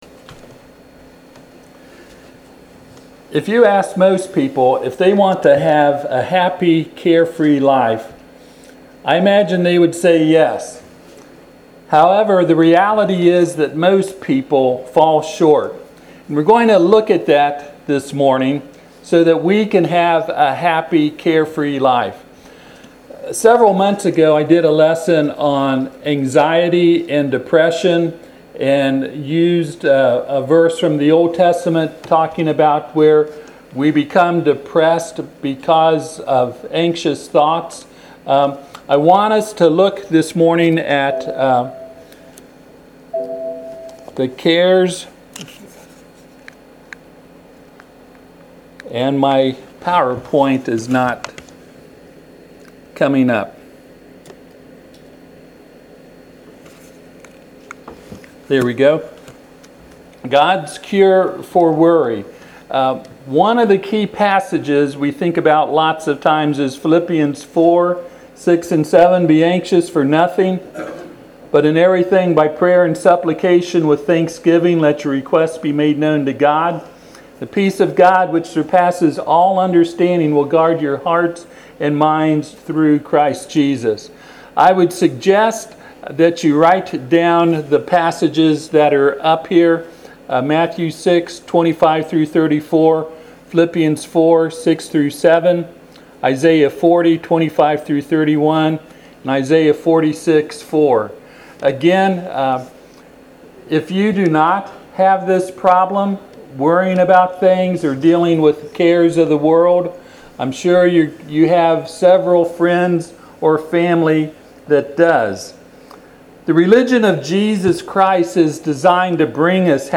Matthew 6:25-34 Service Type: Sunday AM Topics: Anxiety , Depression , despair , Hope « Does Every Person Have A Right To His Own Belief?